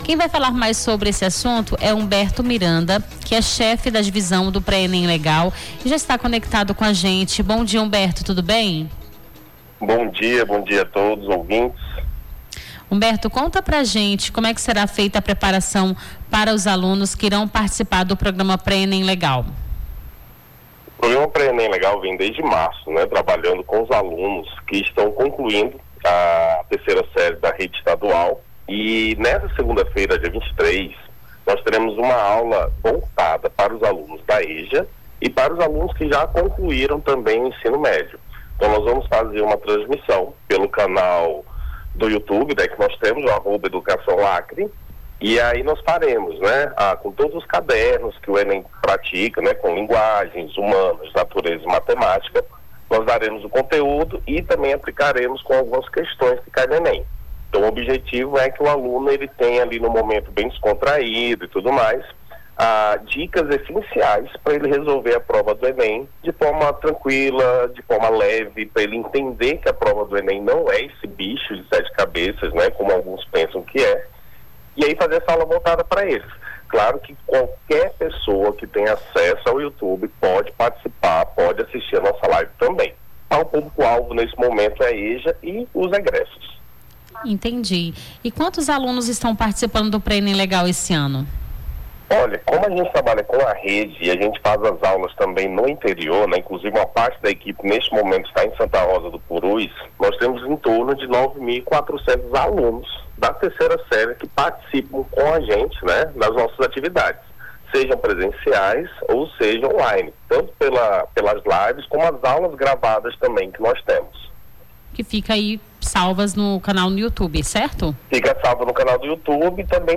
Nome do Artista - CENSURA - ENTREVISTA (AULÃO PRÉ ENEM LEGAL) 18-06-25.mp3